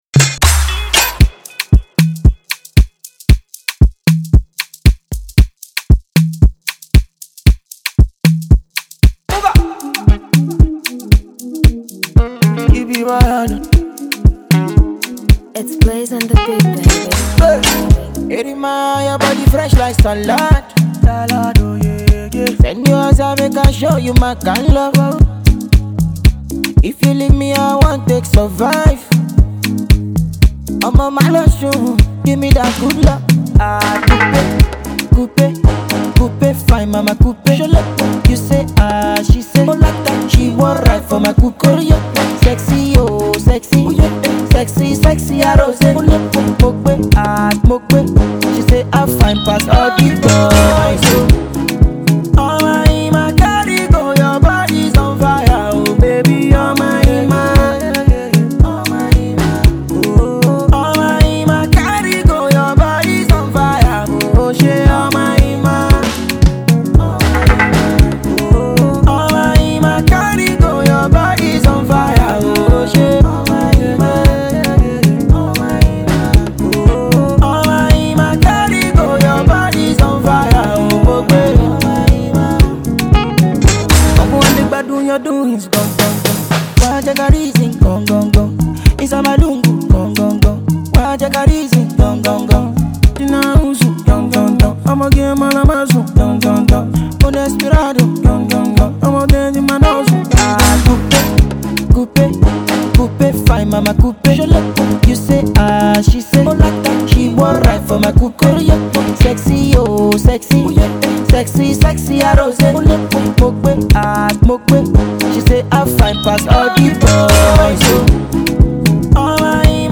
The hook is catchy and the beat is banging.
up-tempo beat